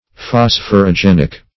Meaning of phosphorogenic. phosphorogenic synonyms, pronunciation, spelling and more from Free Dictionary.